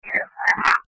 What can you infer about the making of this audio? Saturday, June 16th 2007 - We investigated a family home in Franklin, Massachusetts